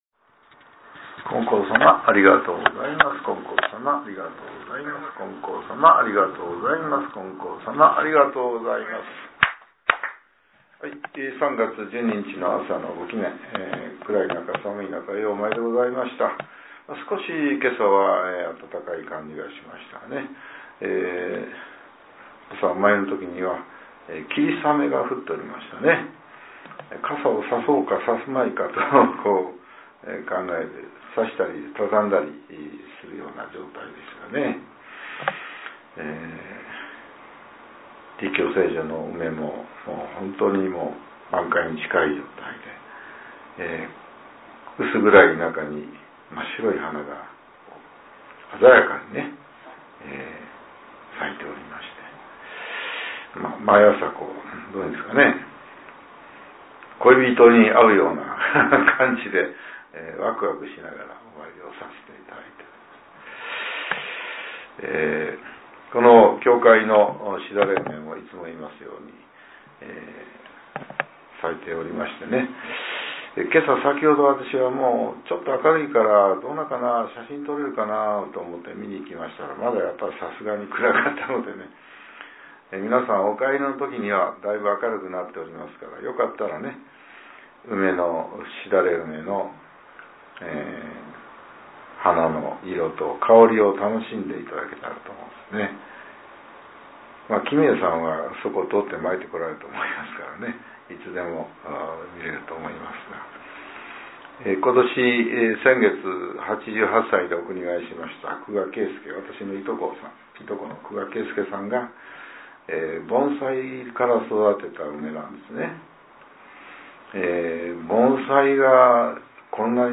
令和７年３月１２日（朝）のお話が、音声ブログとして更新されています。